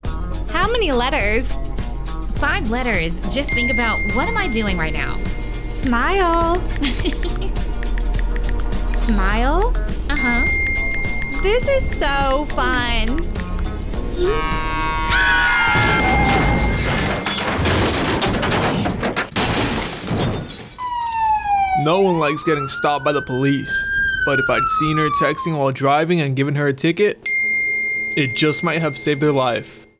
Safe Driving Radio Spot